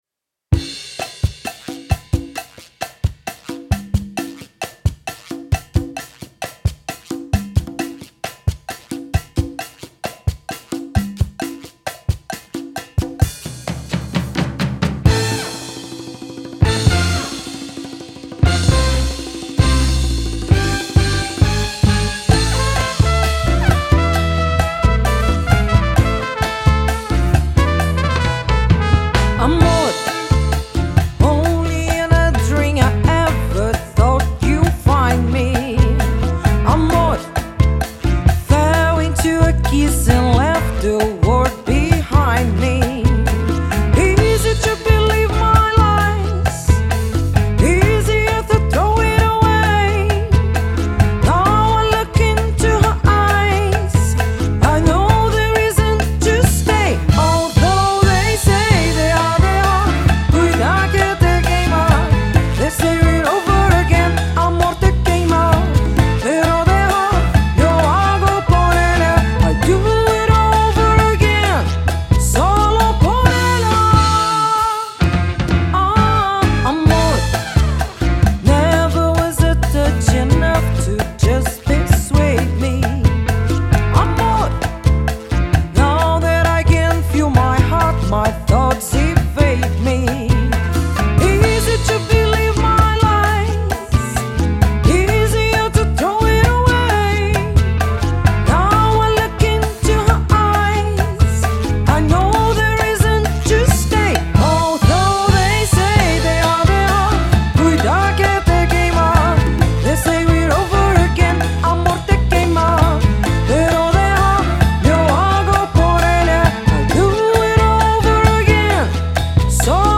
Rio Carnival Samba, Salsa, Latin Jazz...